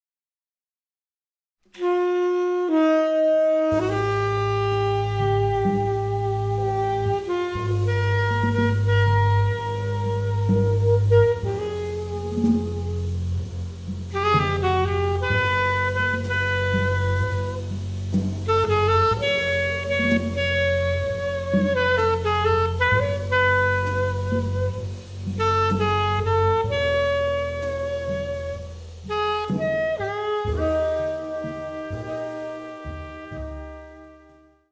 sax
trumpet
bass
drums